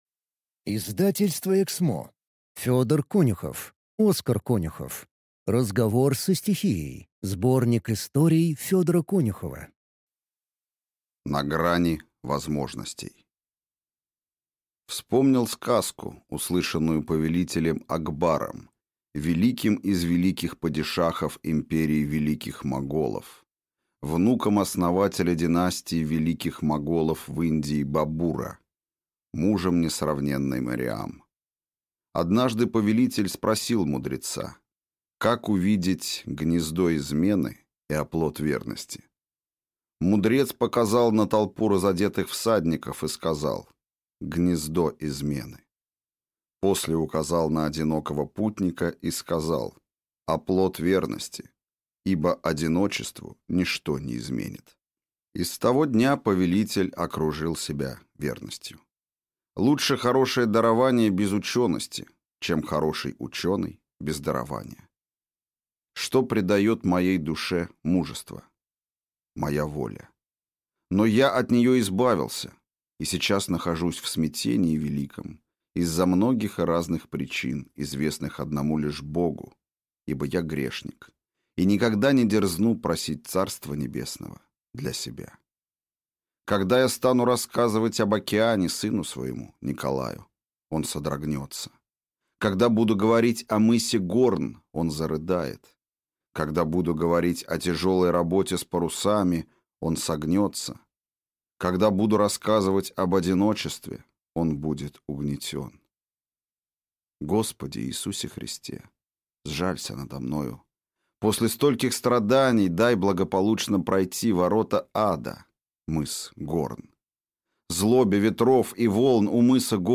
Контрактор. Книга 2 (слушать аудиокнигу бесплатно